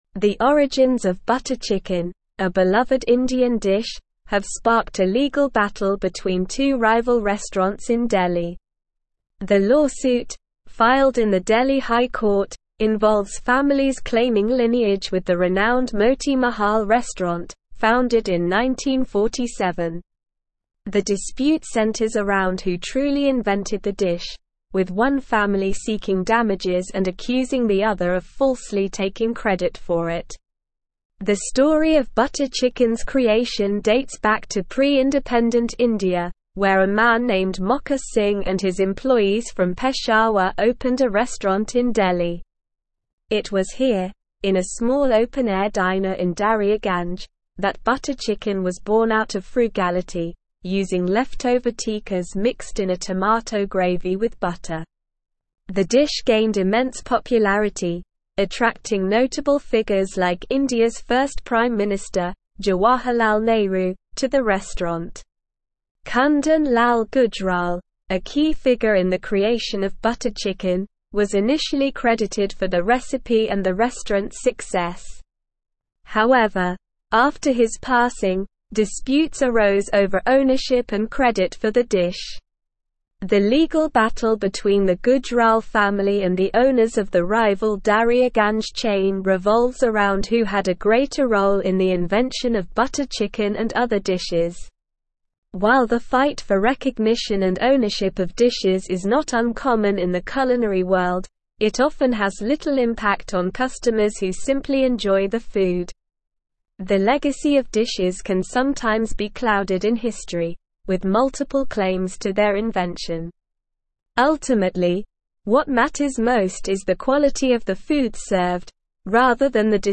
Slow
English-Newsroom-Advanced-SLOW-Reading-Butter-Chicken-Origins-Delhi-Restaurants-in-Legal-Battle.mp3